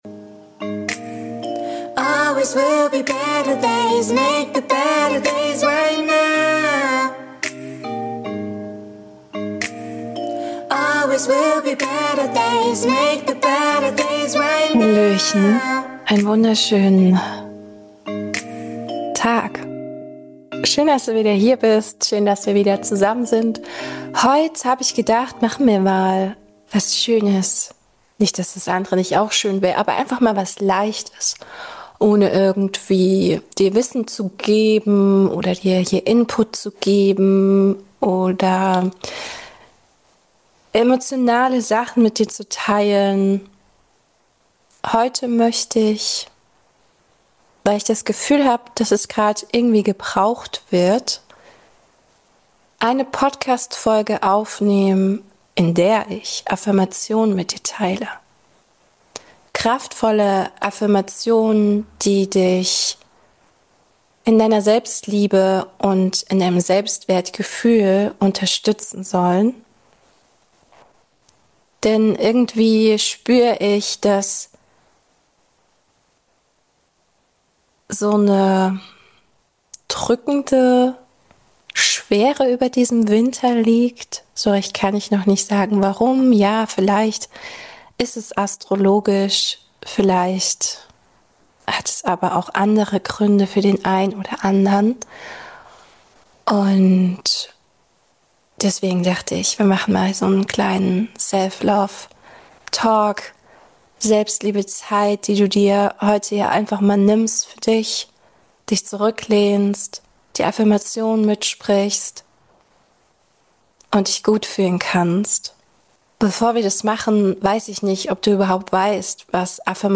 Du darfst ankommen, zur Ruhe kommen, dein Herz öffnen und einfach sein - ganz ohne Druck. Mit diesen 15 sanften Selbstliebe Affirmationen, darfst du dich erinnern, dass du genug bist.